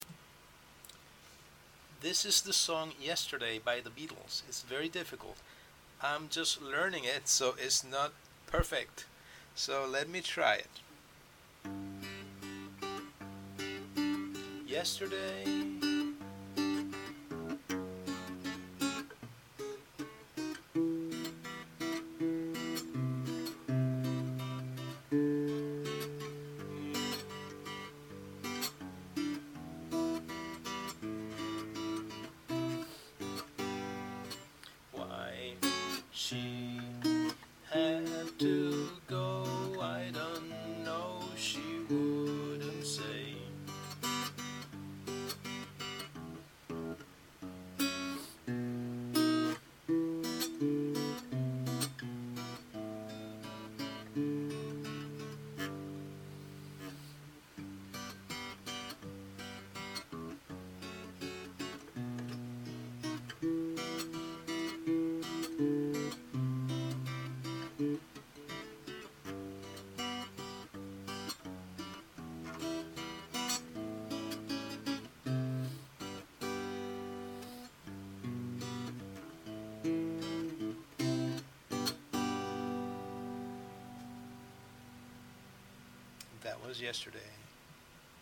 Guitar practice Yesterday
guitar beginner practice Yesterday